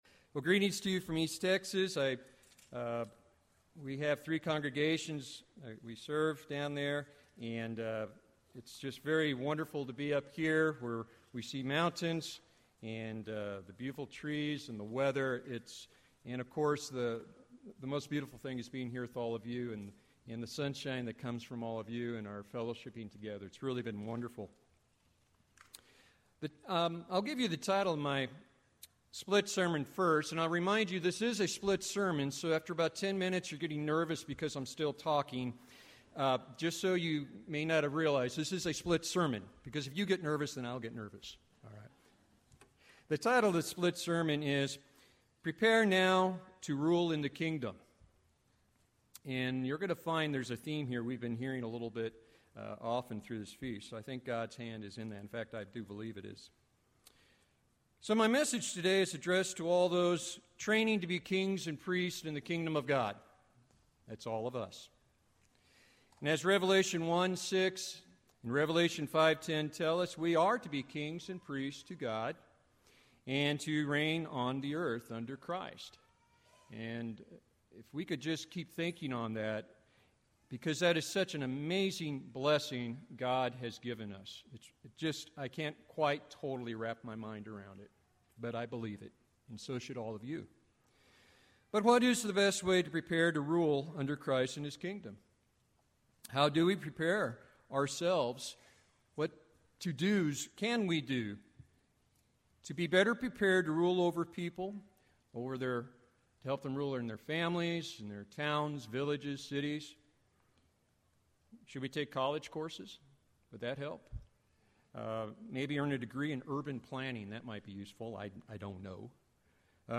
This sermon was given at the Snowshoe, West Virginia 2018 Feast site.